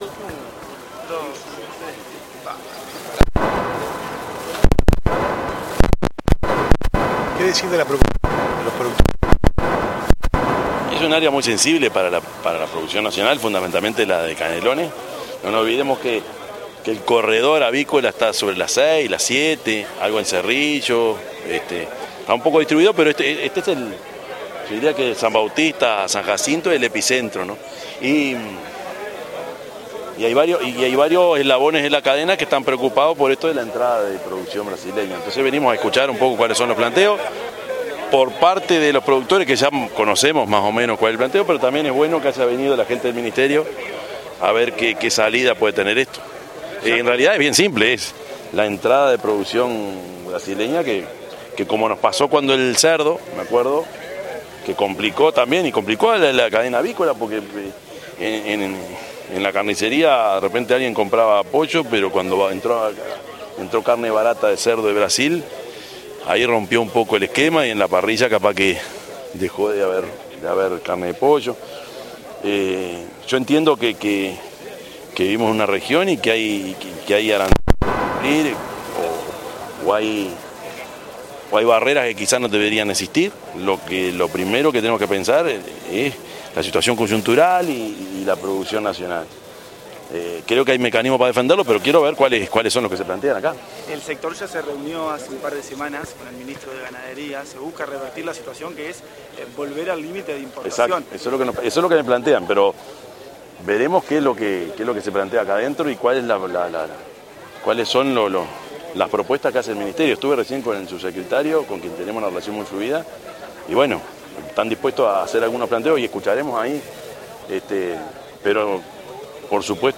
intendente_de_canelones_prof._yamandu_orsi_0.mp3